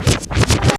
WET SCRATCH.wav